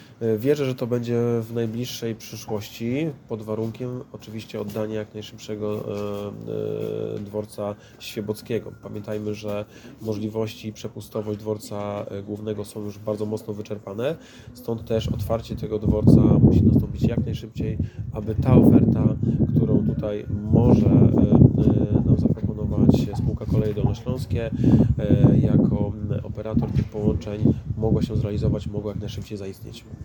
Na zorganizowanym dziś na dworcu kolejowym w Smolcu briefingu, burmistrz Miasta i Gminy Kąty Wrocławskie – Julian Żygadło mówił o rozwoju współpracy pomiędzy gminą a Kolejami Dolnośląskimi.